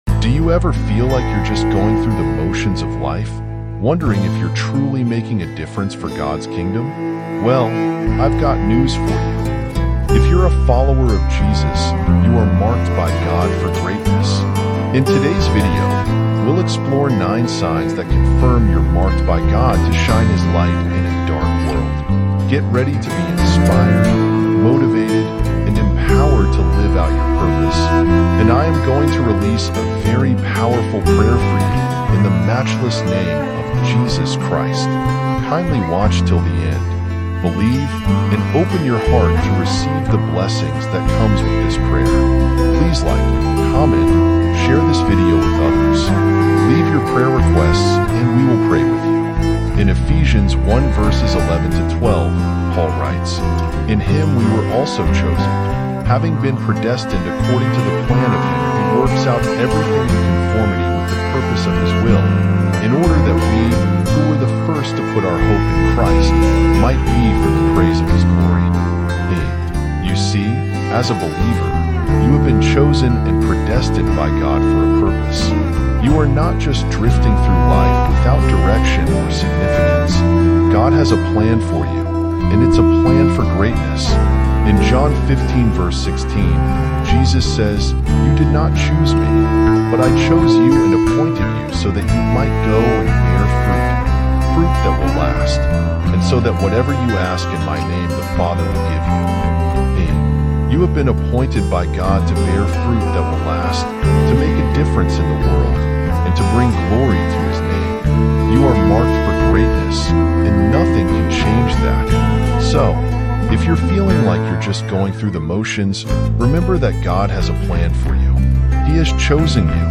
All advertisements are placed at the very beginning of the episode so nothing interrupts the experience once the story begins.